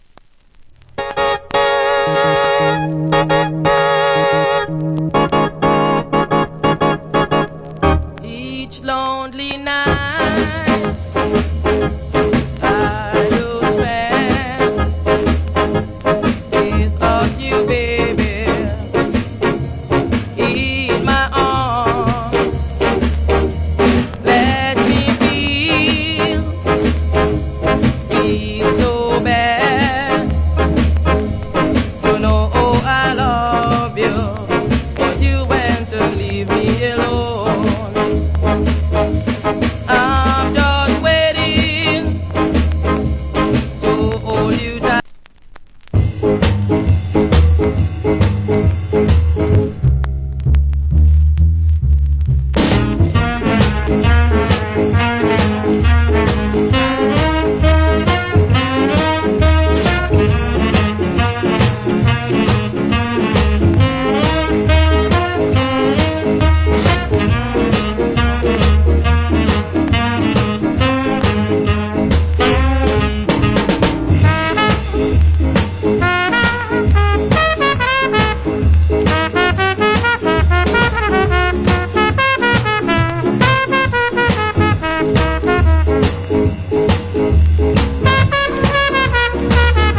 Wicked JA R&B Vocal.